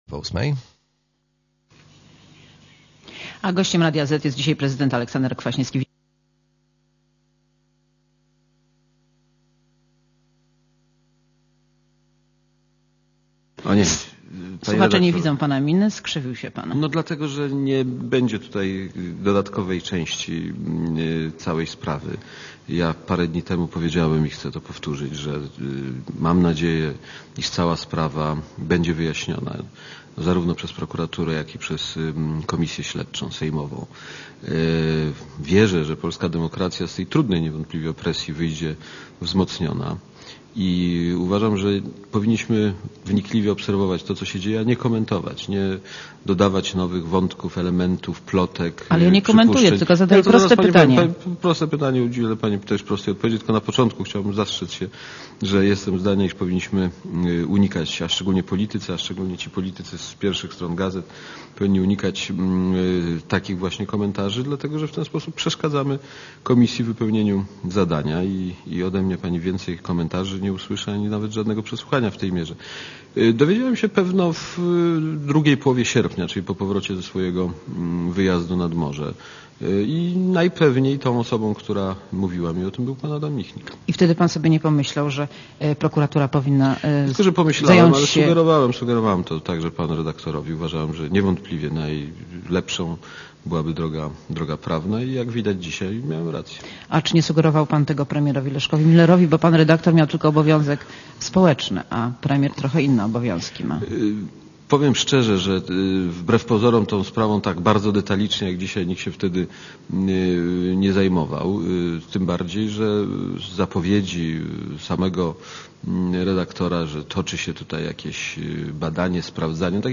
Monika Olejnik rozmawia z prezydentem Aleksandrem Kwaśniewskim